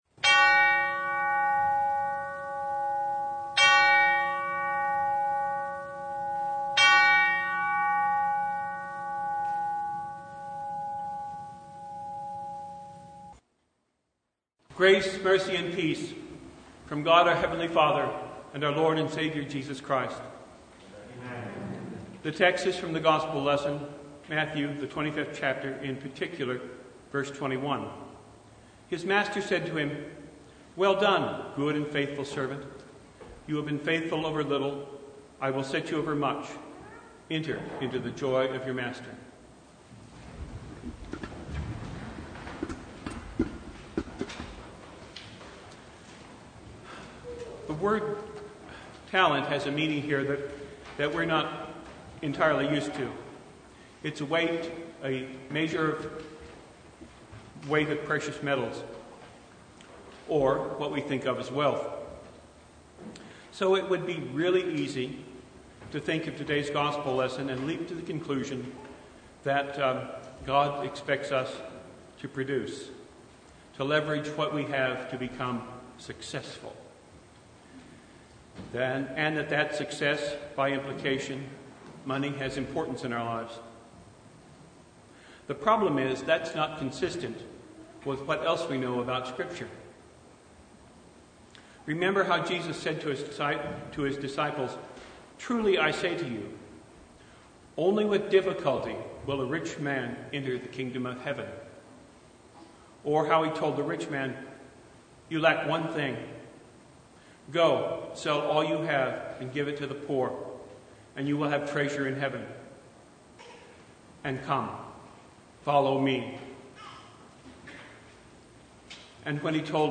November 15, 2020 The Parable of the Talents Passage: Matthew 25:21 Service Type: Sunday Trusting in the Lord and in His gracious work toward us in Christ, we are “good and faithful” stewards.